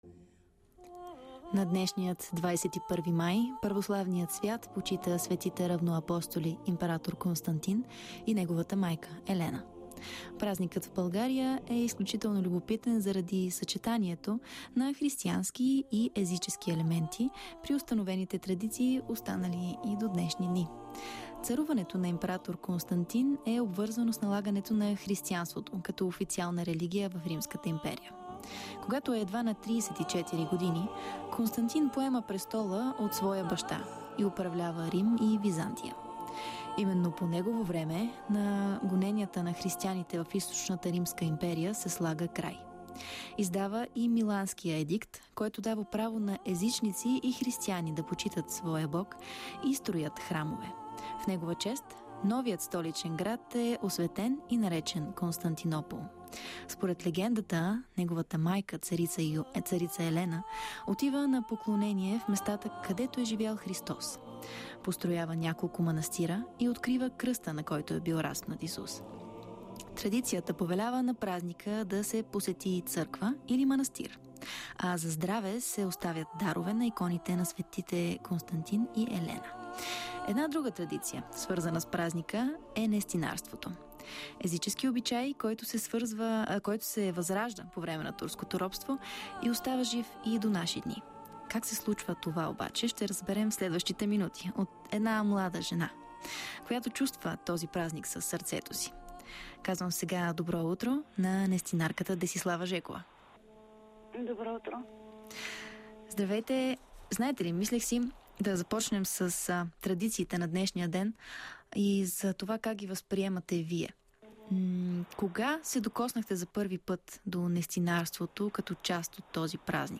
интервю на БНР